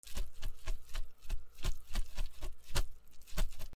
ざる 水をきる
『ザッザ』